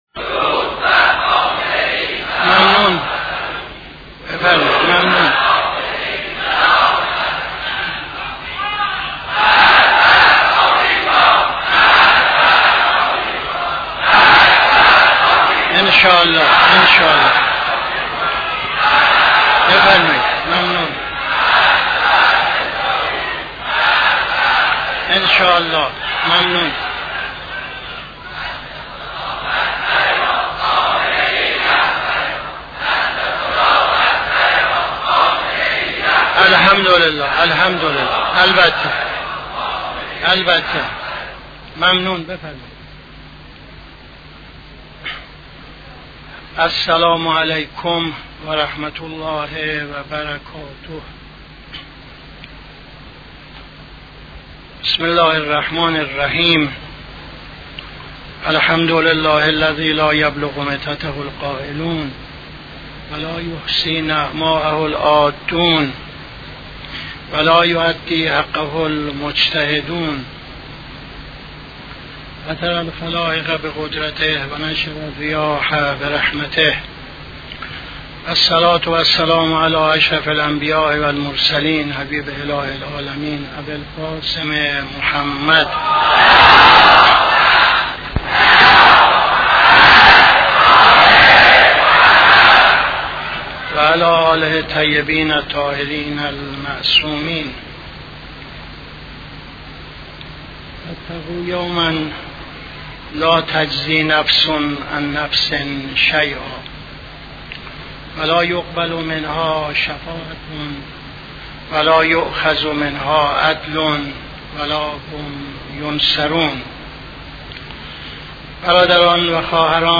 خطبه اول نماز جمعه 27-01-78